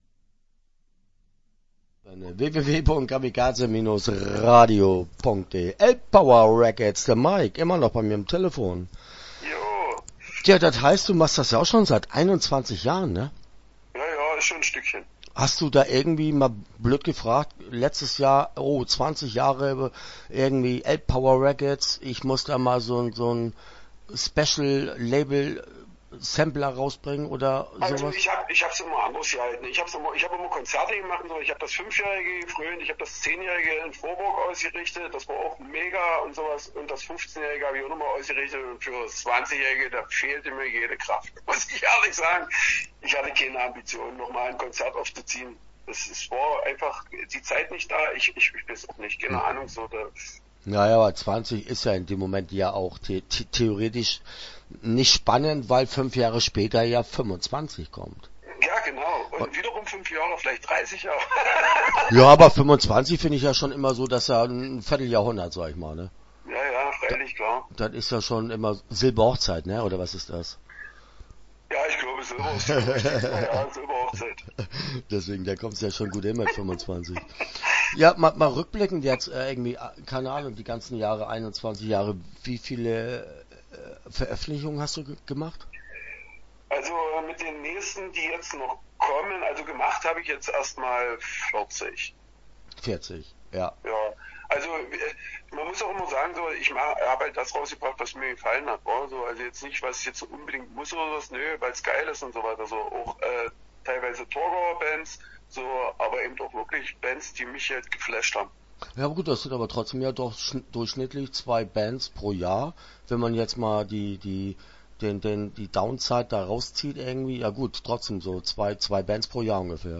Elb Power Records - Interview Teil 1 (13:34)